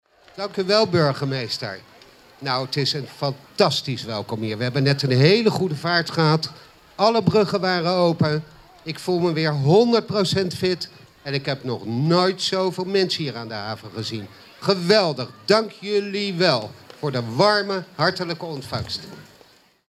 Sinterklaas sprak daarna ook.
Sinterklaas-beantwoordt-verwelkoming-door-burgemeester-Leendert-de-Lange.mp3